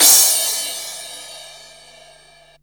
CRASH03   -R.wav